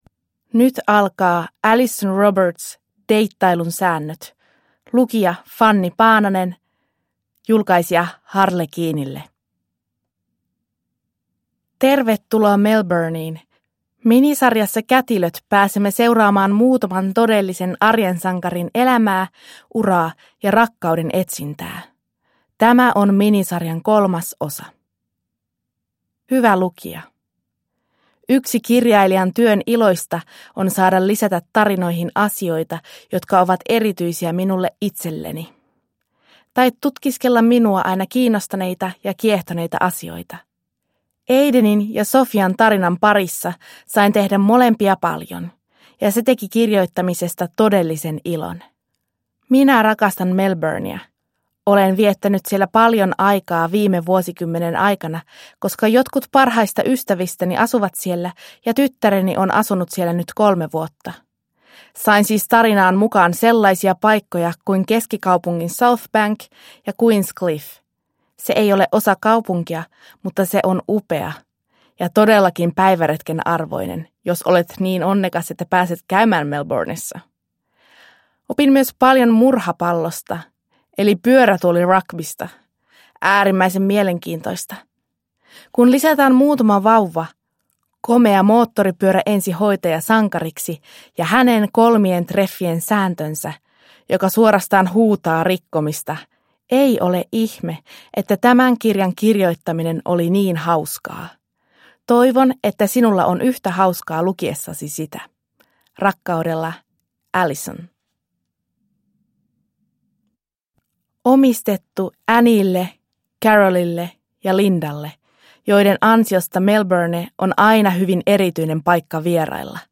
Deittailun säännöt – Ljudbok – Laddas ner